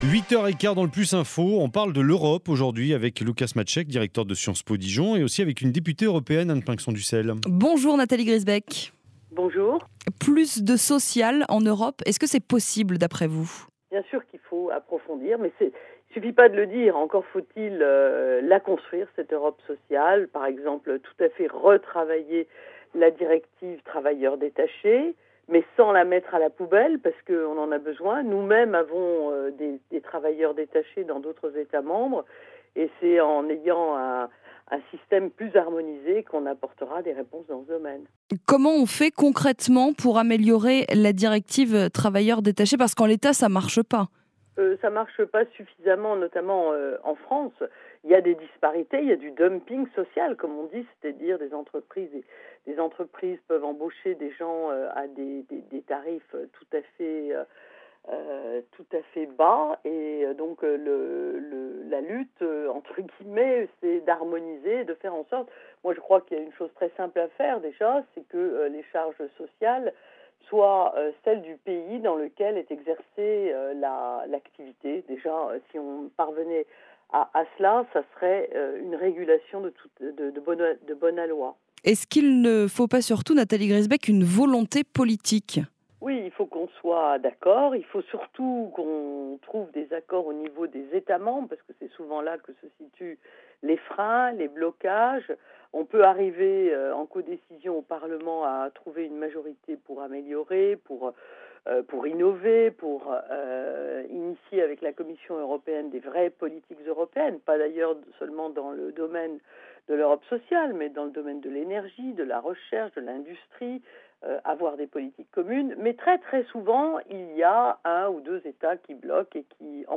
Nathalie Griesbeck était l’invitée de France Bleu Bourgogne : Europe sociale, harmonisation, place de la France dans l’UE et approfondissement étaient au menu des échanges. Retrouvez ci-dessous l’intégralité de son intervention dans l’émission + info du 10 mai dernier.